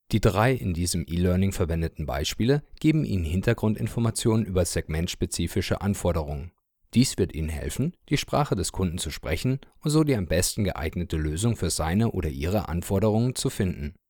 German native speaker with more than 7 years of professional Voice Over experience for all your audio needs.
Sprechprobe: eLearning (Muttersprache):